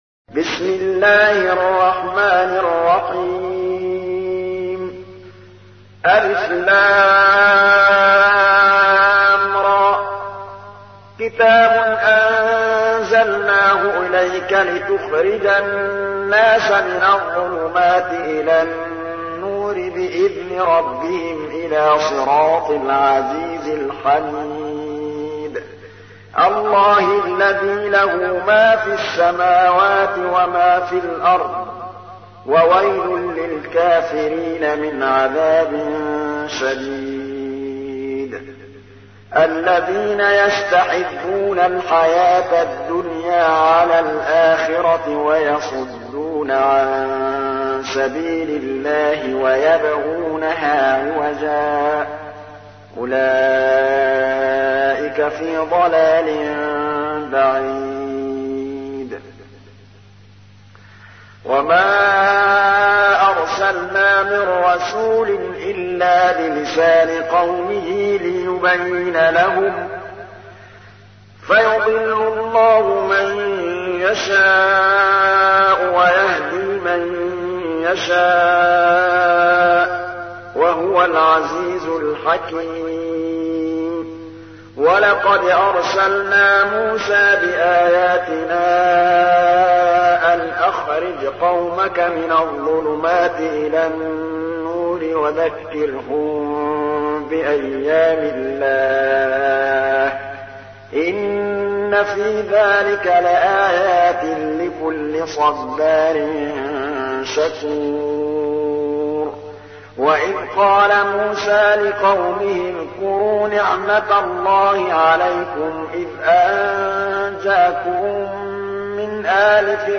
تحميل : 14. سورة إبراهيم / القارئ محمود الطبلاوي / القرآن الكريم / موقع يا حسين